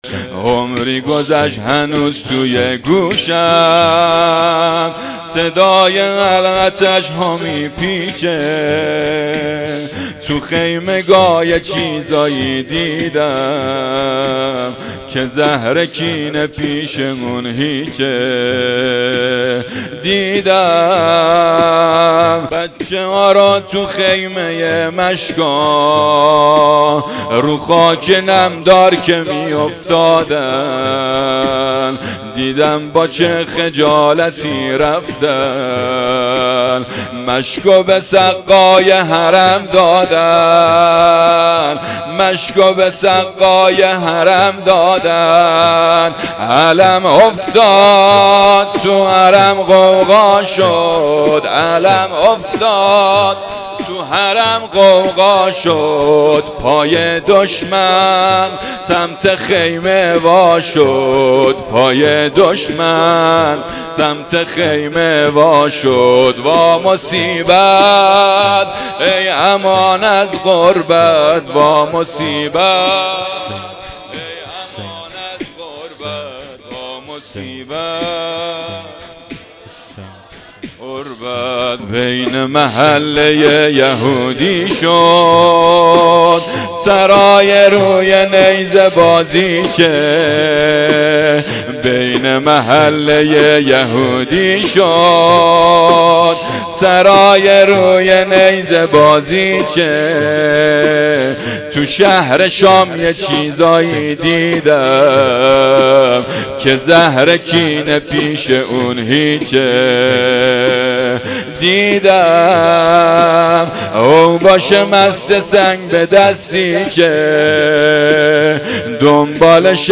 هیئت جنة البقیع - زمینه | عمری گذشت هنوز توی گوشم